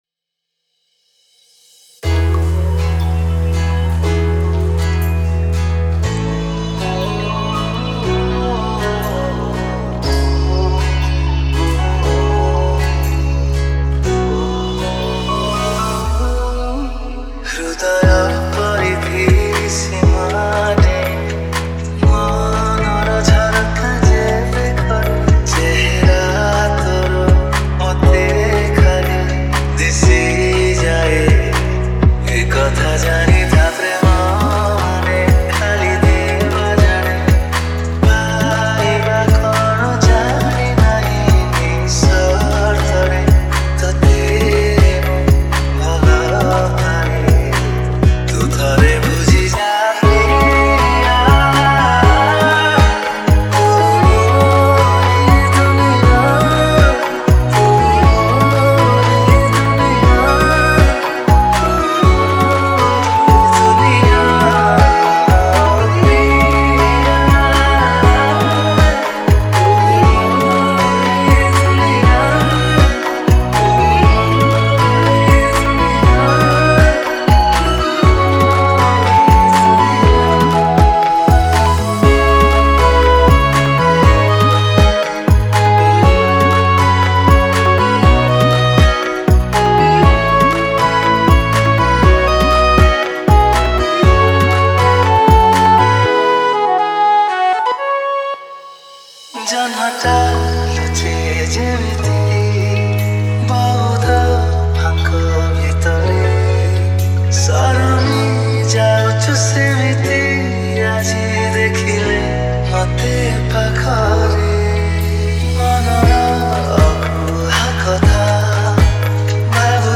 Romantic Love Dj Remix Songs Download
Category : Romantic Love Dj Remix